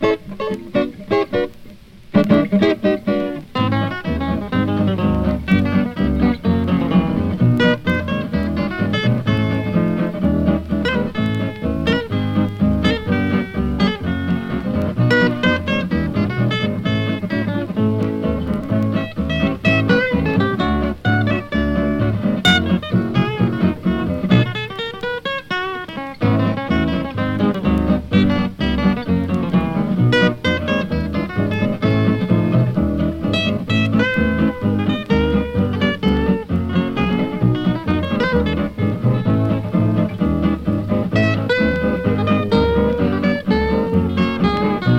カントリーやウェスタンなそのものずばりの出立ちで、ギター奏者のカールとフィドル奏者のヒューの兄弟デュオ。
※元音源に準ずるプチプチ音有り
Country, Western Swing, Jazz　Germany　12inchレコード　33rpm　Mono